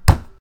ButtonHit.ogg